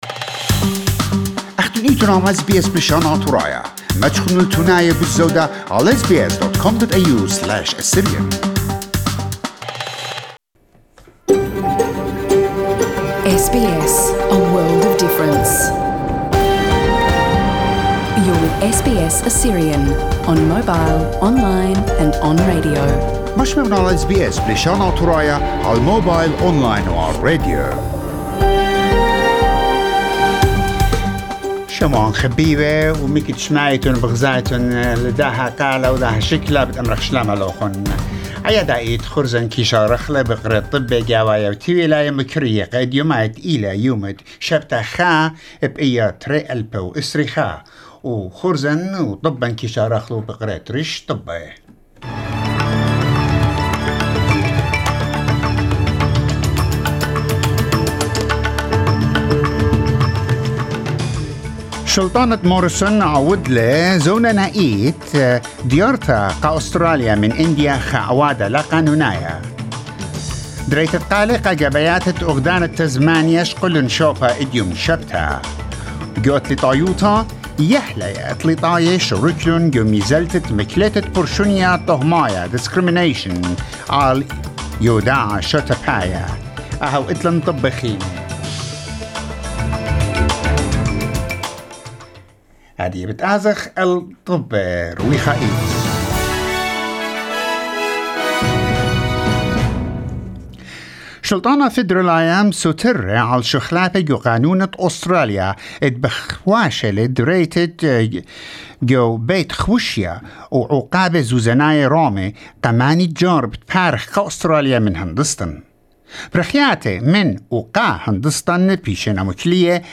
Assyrian News 01/05/2001